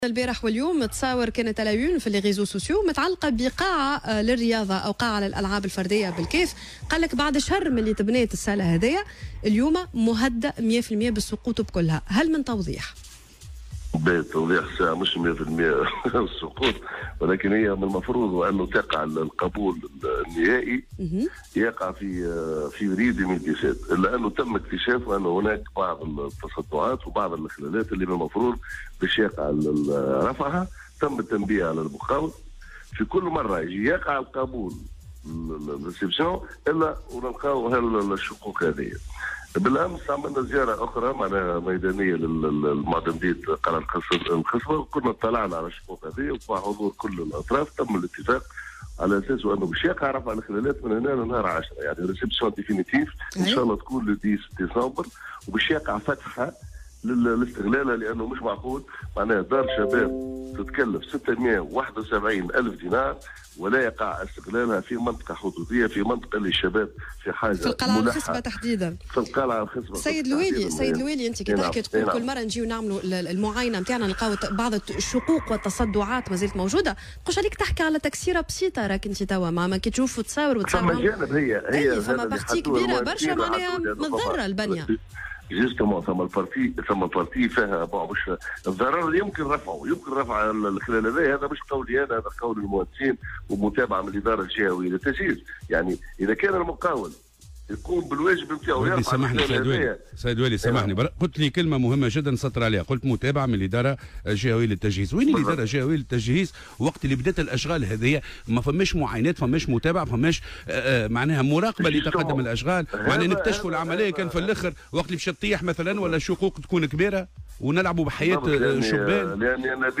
وقال والي الكاف، منور الورتاني في مداخلة له اليوم في برنامج "صباح الورد" على "الجوهرة أف أم" أنه تم اكتشاف بعض التصدعات والإخلالات وتم التنبيه على المقاول لرفعها، مشيرا إلى أنه قام أمس بزيارة ميدانية للمكان وتم الاتفاق على الإسراع في رفع هذه الإخلالات قبل يوم 10 ديسمبر المقبل وفتح المقر للاستغلال.